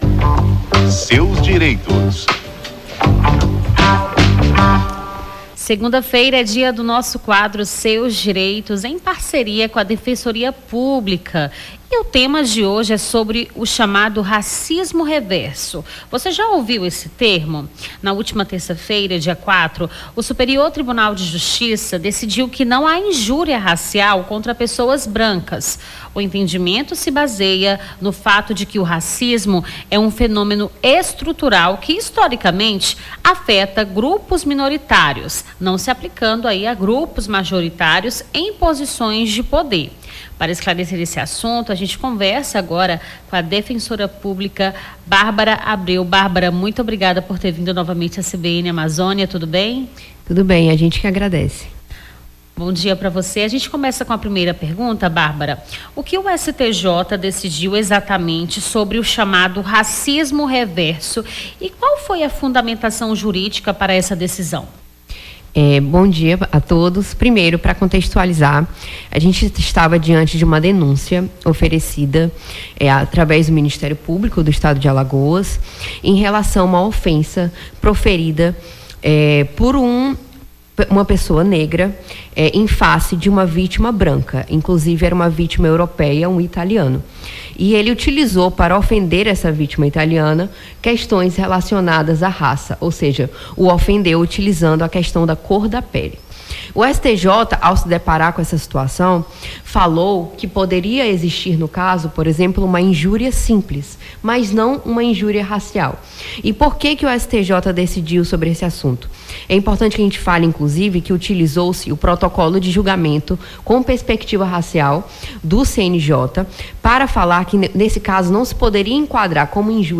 as apresentadoras
conversaram